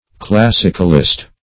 Search Result for " classicalist" : The Collaborative International Dictionary of English v.0.48: Classicalist \Clas"sic*al*ist\, n. One who adheres to what he thinks the classical canons of art.